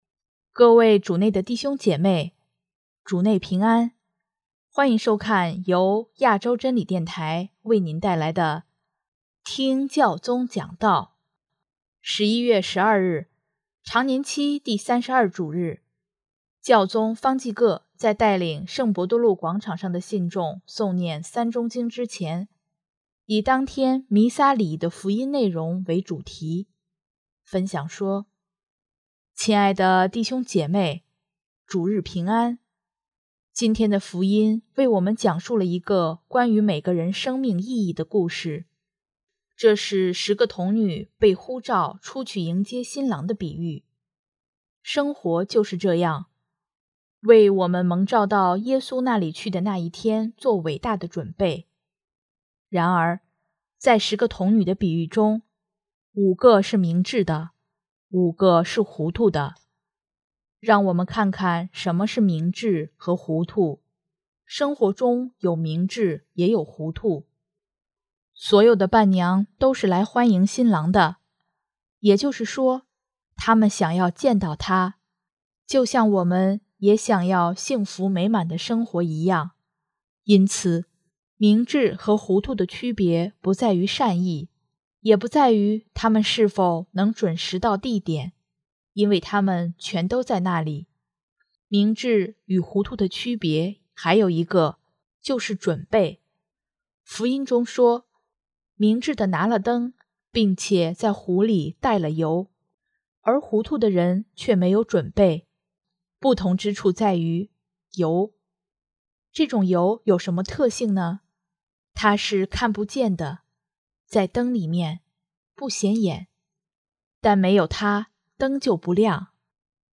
【听教宗讲道】|珍惜内在生命的油
11月12日，常年期第三十二主日，教宗方济各在带领圣伯多禄广场上的信众诵念《三钟经》之前，以当天弥撒礼仪的福音内容为主题，分享说：